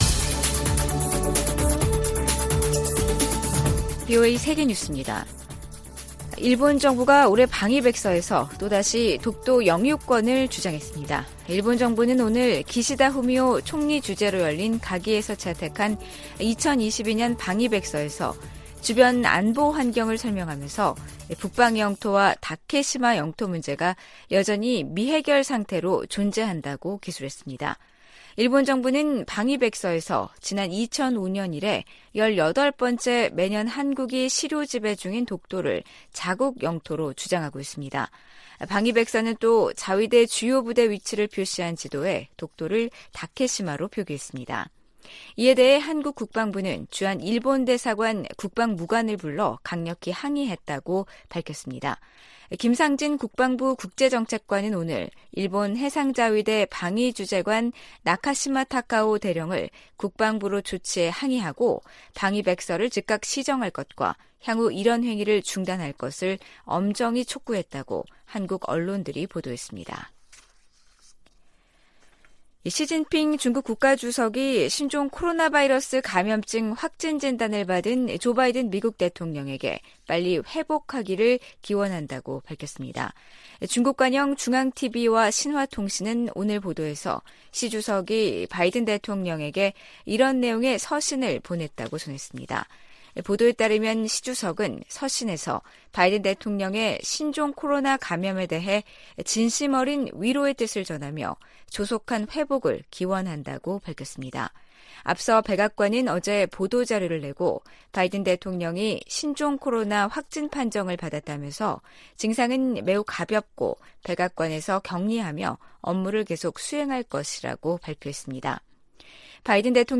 VOA 한국어 간판 뉴스 프로그램 '뉴스 투데이', 2022년 7월 22일 3부 방송입니다. 한국 국방부는 대규모 미-한 연합연습과 야외기동훈련을 올해부터 부활시킬 방침이라고 밝혔습니다. 미국은 한국과 일본의 핵무장을 절대 지지하지 않을 것이라고 고위 관리가 전망했습니다.